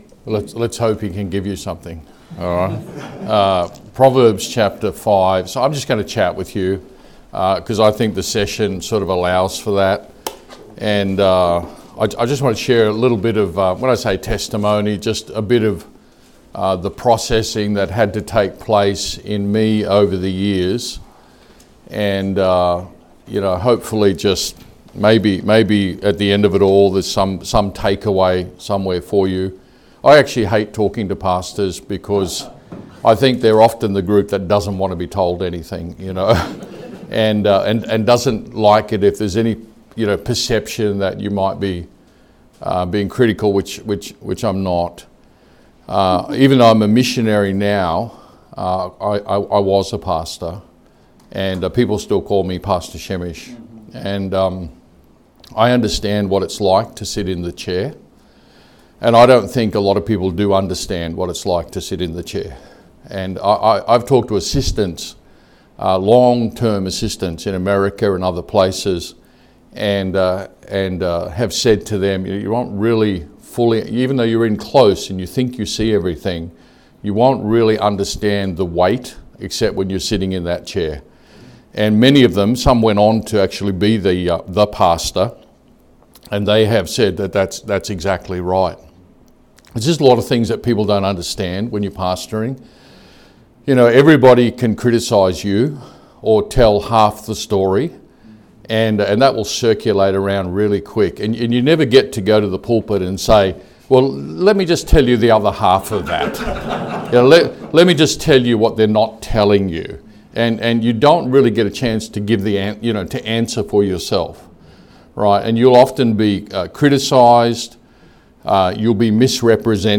Sermons | Good Shepherd Baptist Church
Leadership Conference 2024